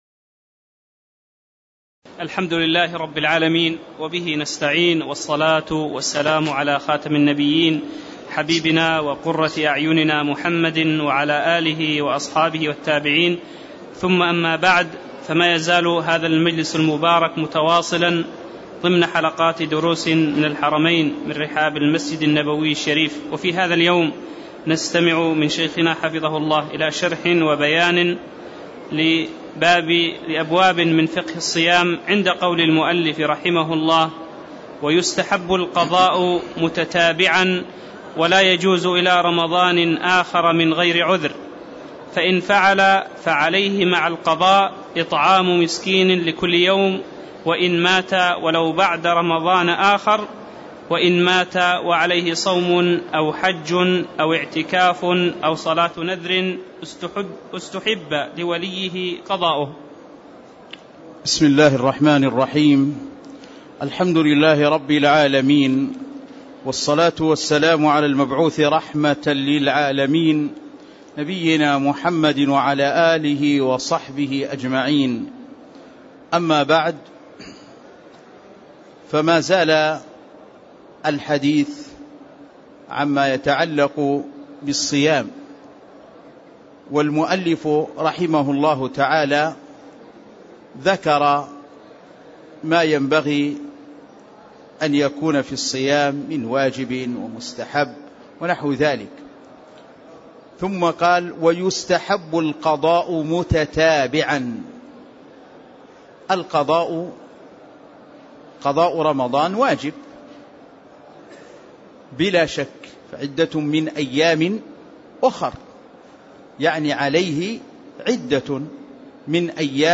تاريخ النشر ١٢ جمادى الأولى ١٤٣٦ هـ المكان: المسجد النبوي الشيخ